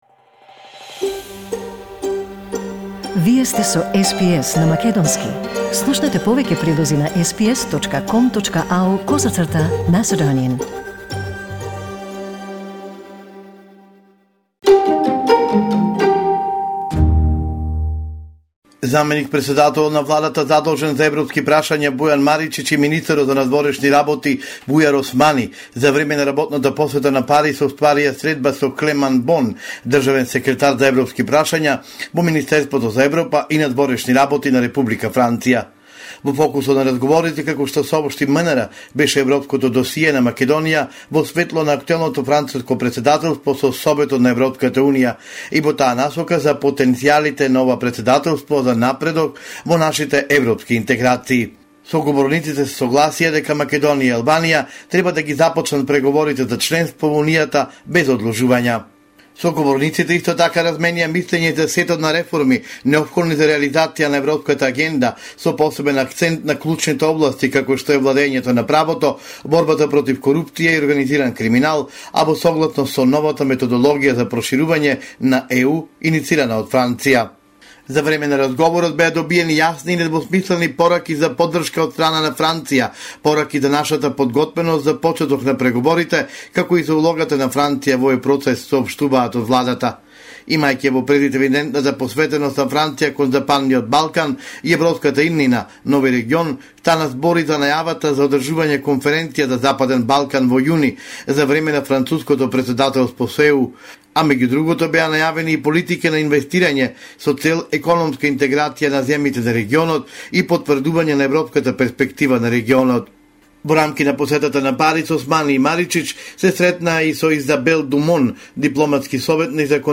Homeland Report in Macedonian 3 February 2022